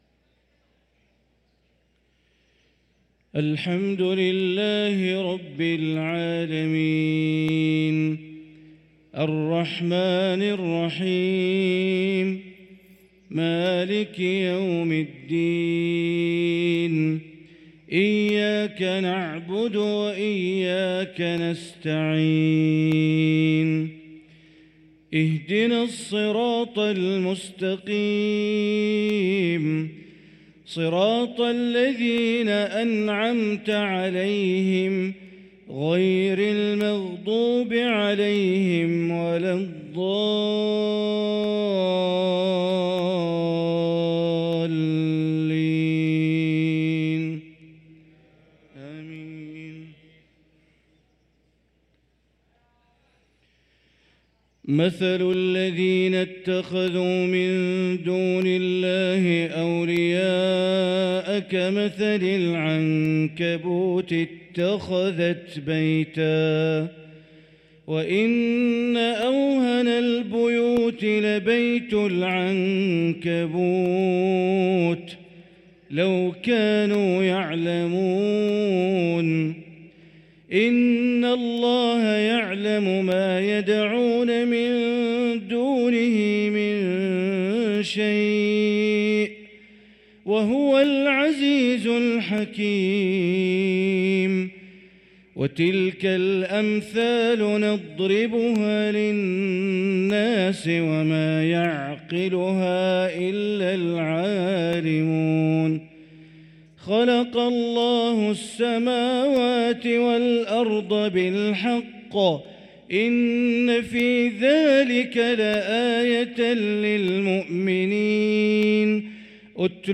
صلاة العشاء للقارئ بندر بليلة 28 جمادي الآخر 1445 هـ